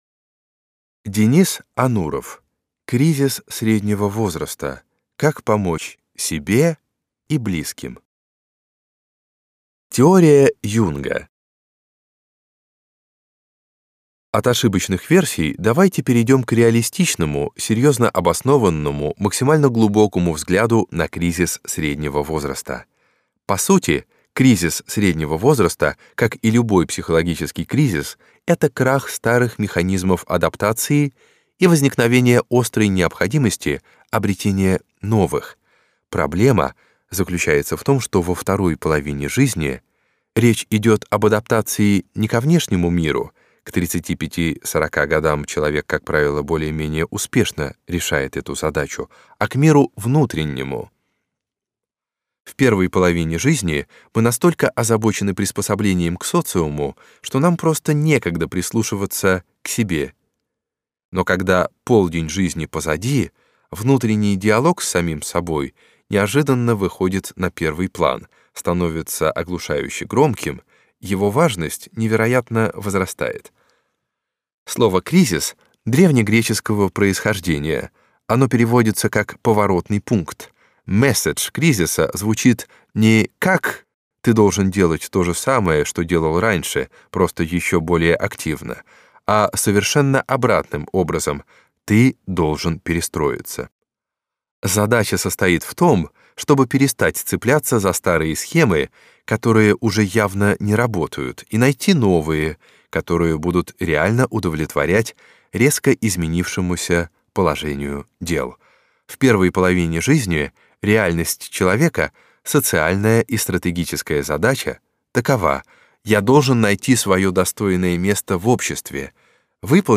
Аудиокнига Кризис среднего возраста. Как помочь себе и близким | Библиотека аудиокниг